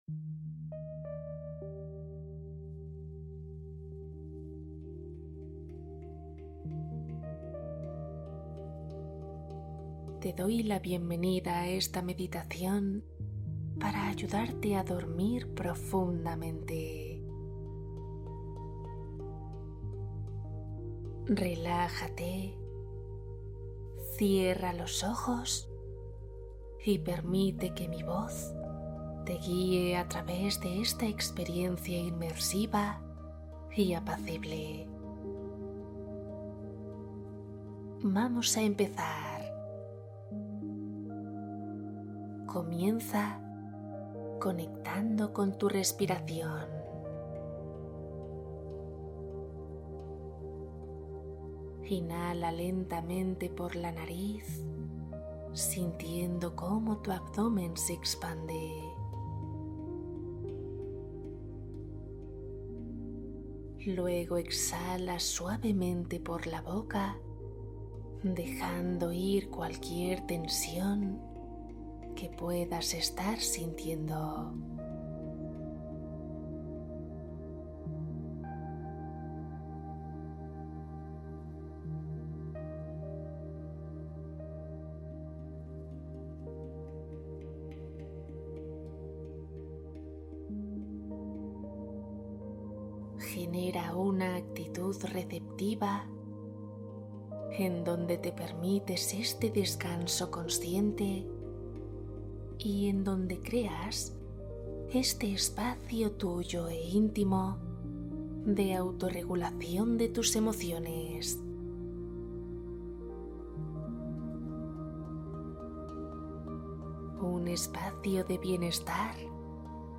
Duerme rápido y relájate Meditación guiada para sueño profundo